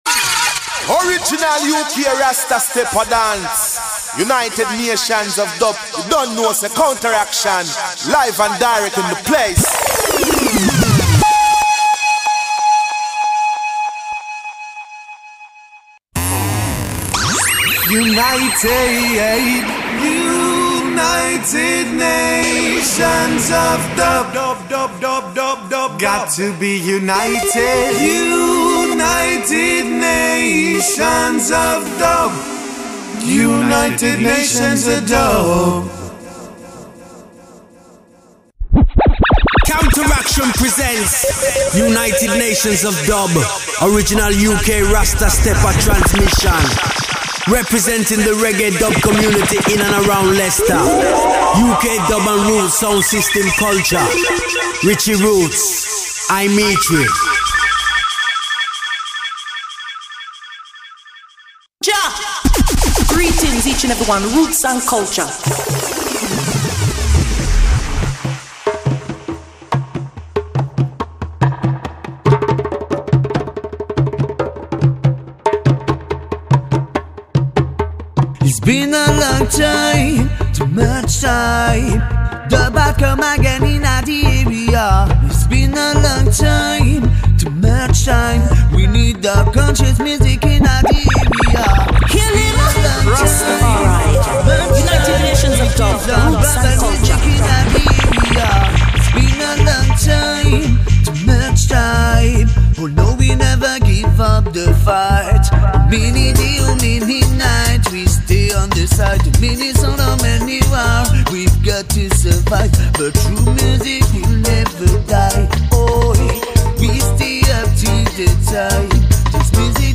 acoustic live in studio